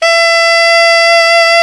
SAX ALTOFF0J.wav